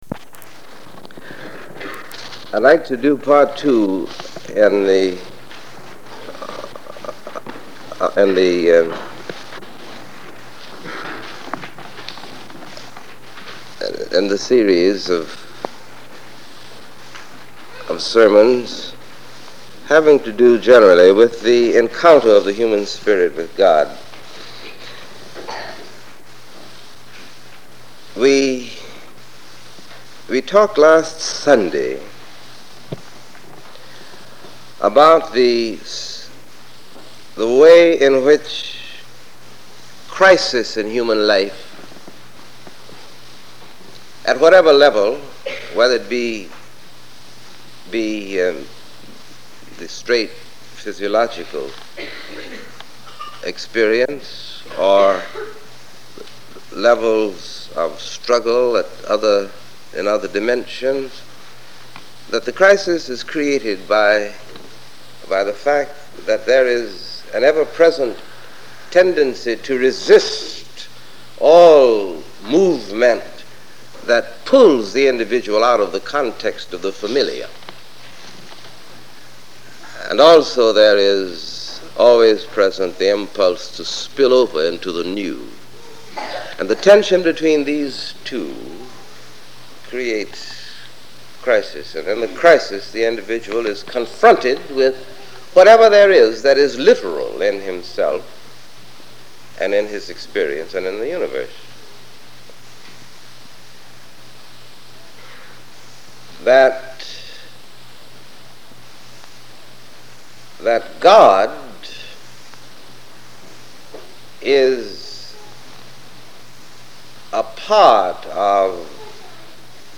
This sermon is the second sermon in a series on The Divine Encounter. This one focuses on the commonplace, or finding sprirituality and God in every day life.